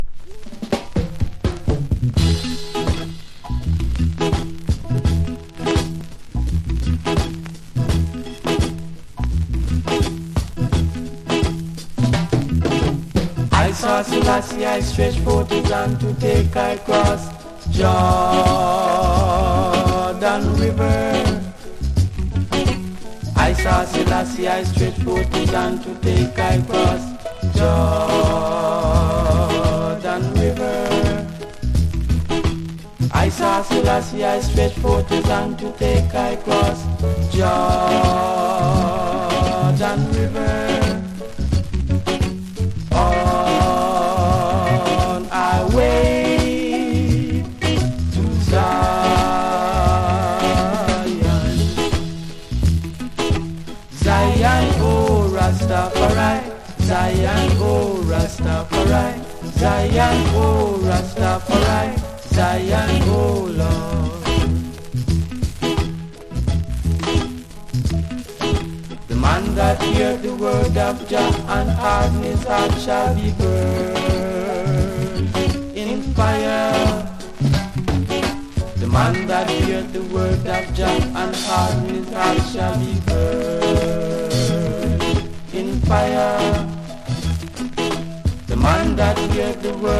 • REGGAE-SKA
お互いのボーカルが重なって、コーラスがとても美しいです。
所によりノイズありますが、リスニング用としては問題く、中古盤として標準的なコンディション。